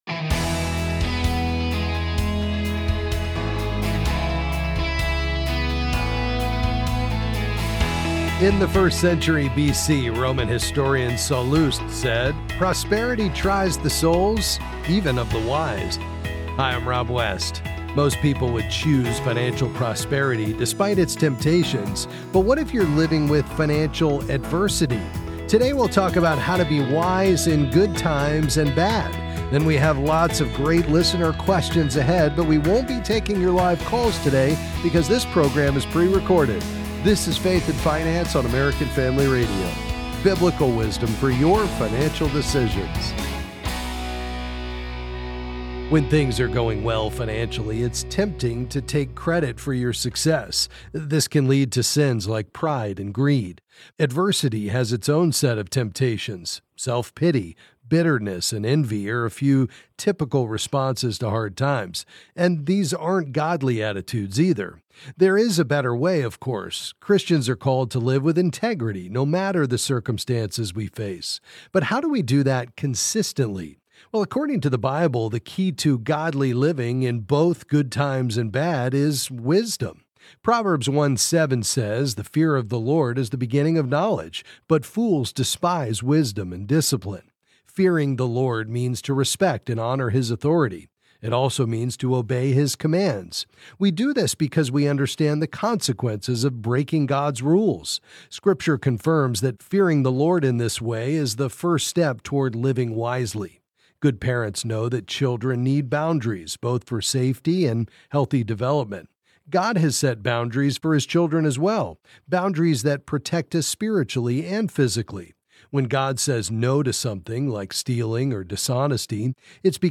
Then he answers some questions on various financial topics.